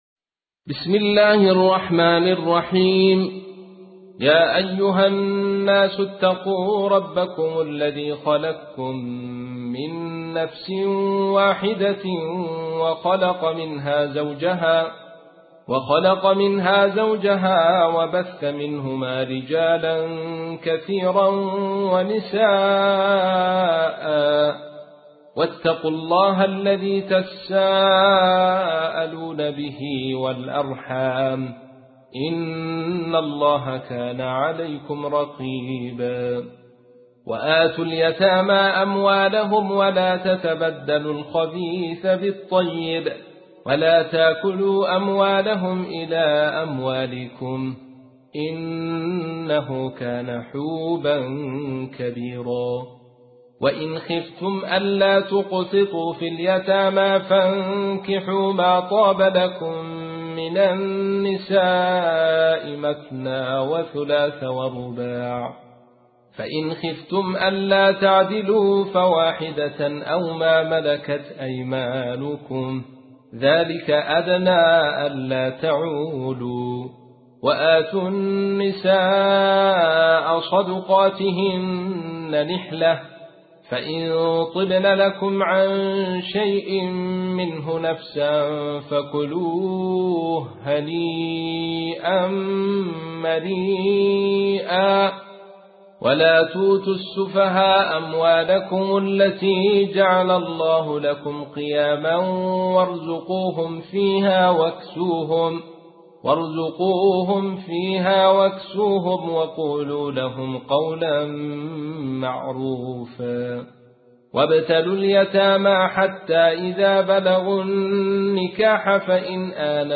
تحميل : 4. سورة النساء / القارئ عبد الرشيد صوفي / القرآن الكريم / موقع يا حسين